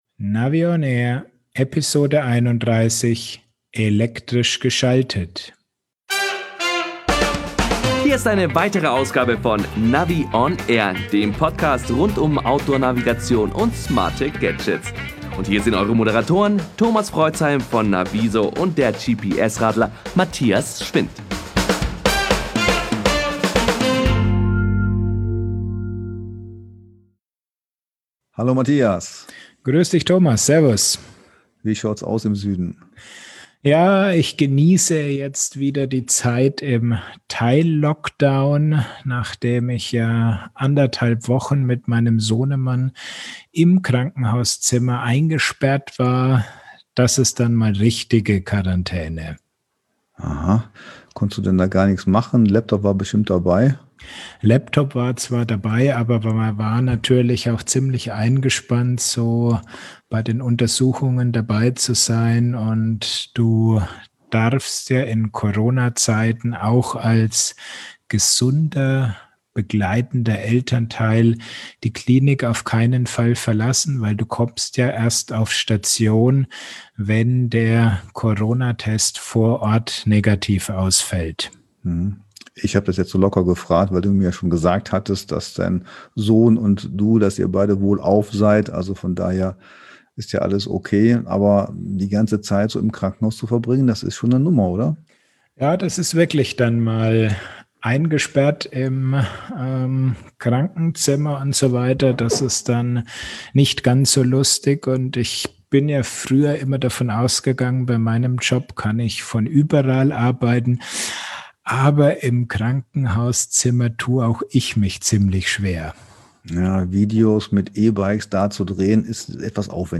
Im Podcast bekommt ihr viele Tipps, Tricks und Hintergrundberichte.